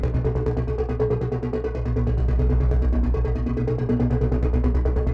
Index of /musicradar/dystopian-drone-samples/Tempo Loops/140bpm
DD_TempoDroneE_140-B.wav